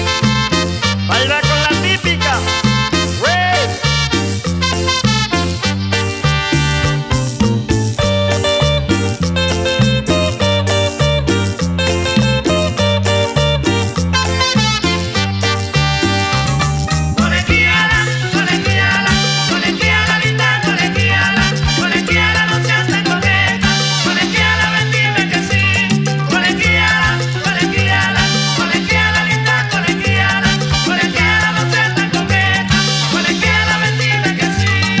Жанр: Латиноамериканская музыка
# Latino